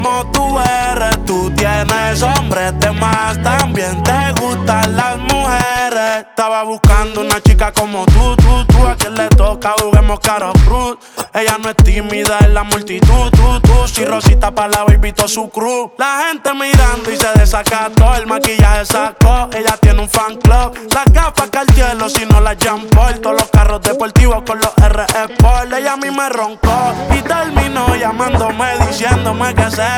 # Pop Latino